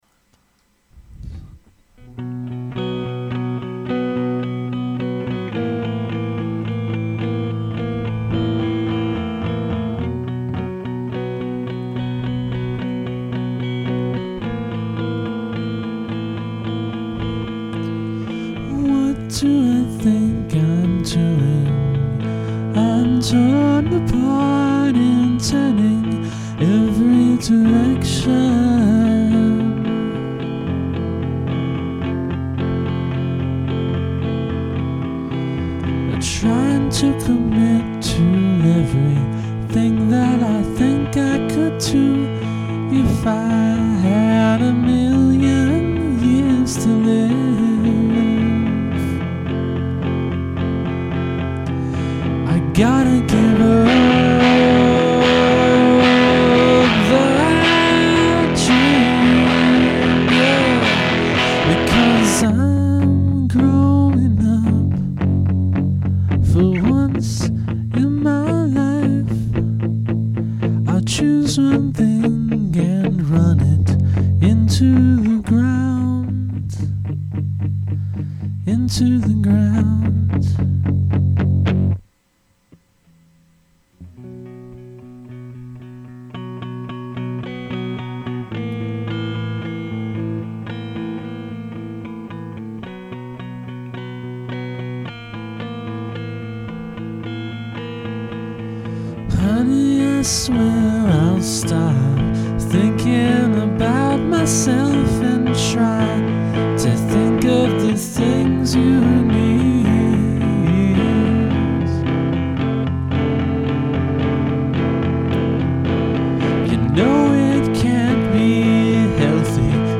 This is just me, and it's pretty spare.
I like it, but would be interested in hearing it with an acoustic guitar or piano as opposed to the electric.
nice, dark
My only reccomendation is I wish your vocal mic was a few feet farther away - some space on your voice would be nice.